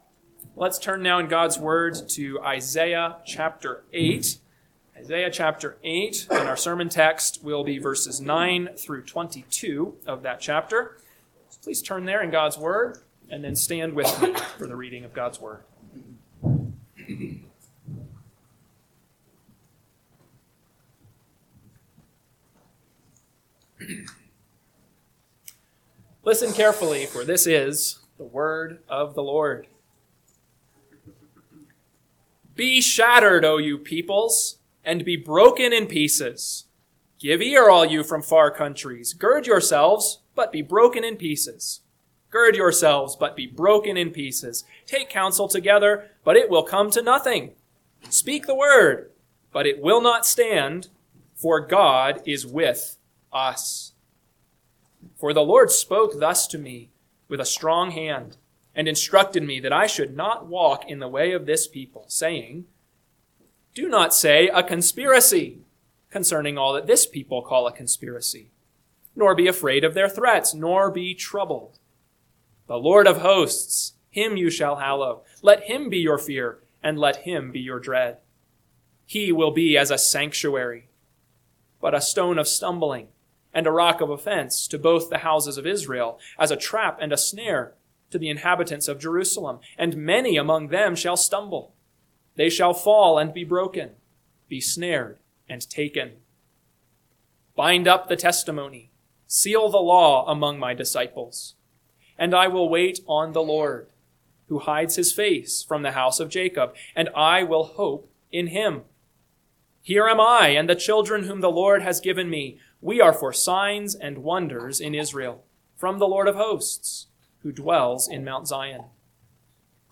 AM Sermon – 1/4/2026 – Isaiah 8:9-22 – Northwoods Sermons